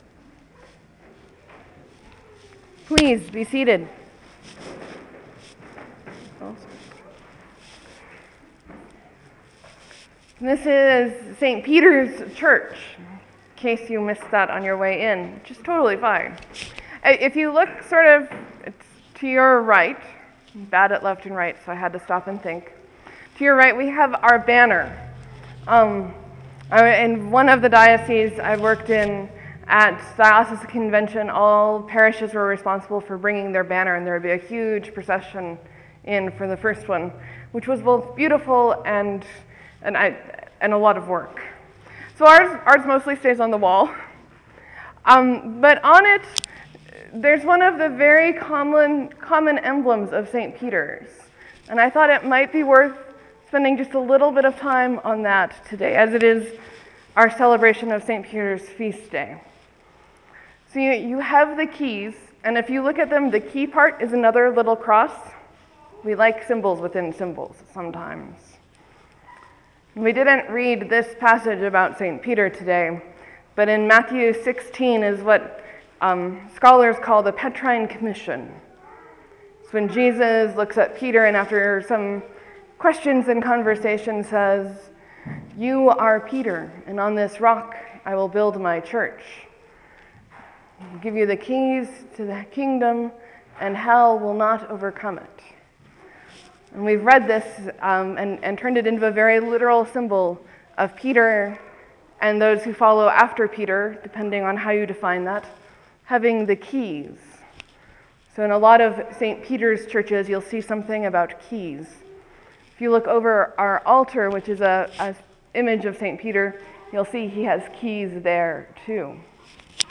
Sermon, , , , , , Leave a comment
(I am using an alternate method of working with the file so if it’s a bit odd this week, there’s a reason.)